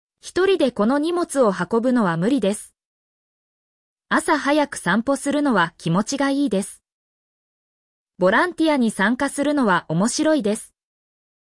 mp3-output-ttsfreedotcom-20_DFr7ijkT.mp3